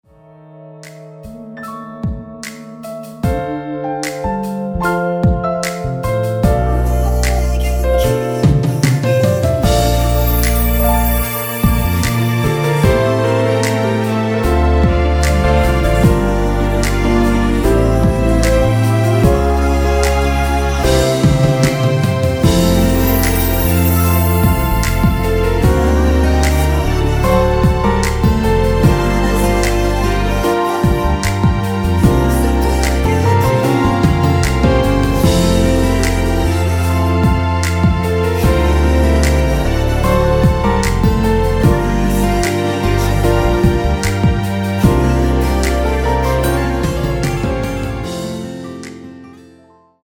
원키에서 (+1)올린 코러스 포함된 MR 입니다.
앞부분30초, 뒷부분30초씩 편집해서 올려 드리고 있습니다.
중간에 음이 끈어지고 다시 나오는 이유는